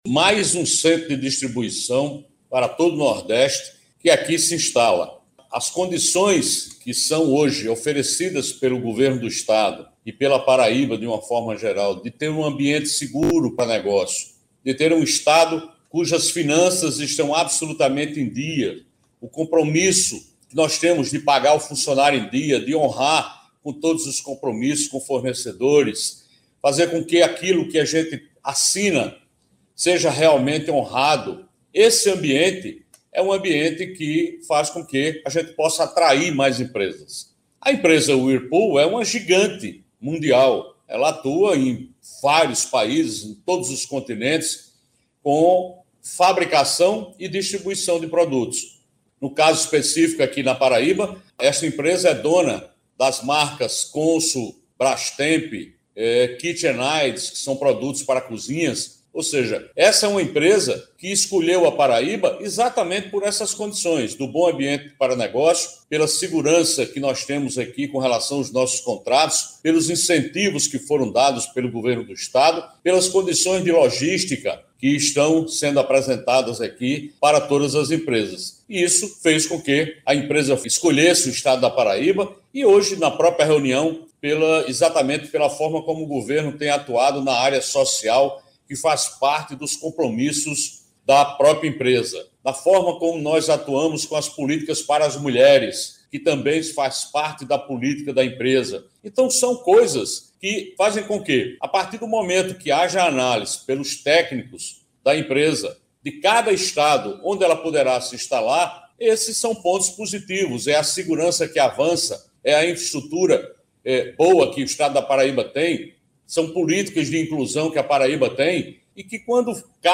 Ouça o governador: